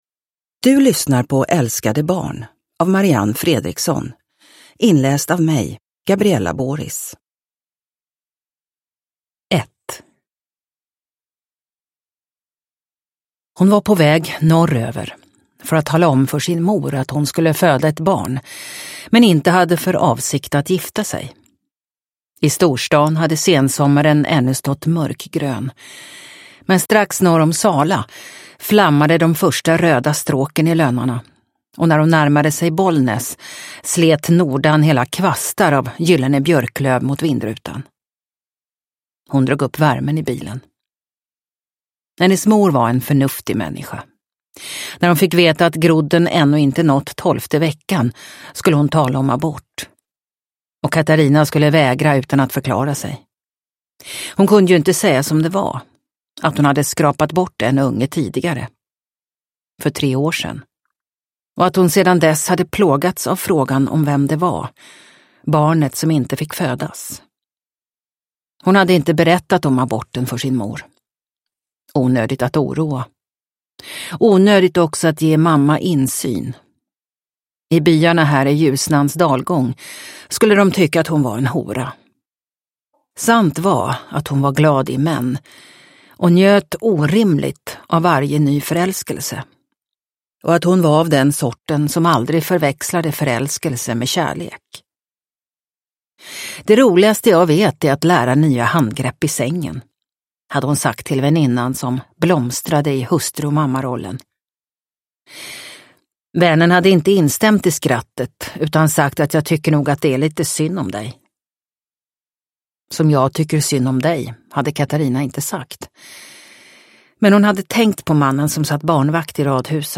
Älskade barn – Ljudbok – Laddas ner